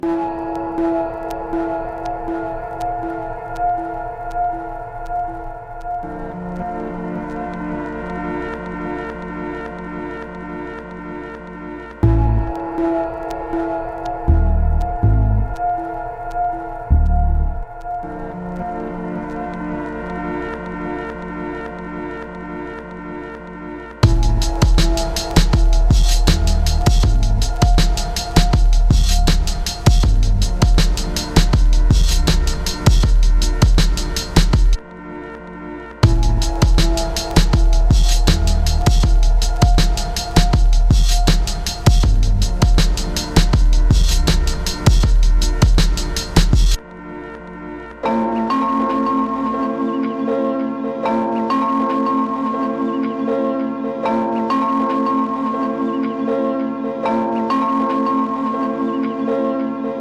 Big beat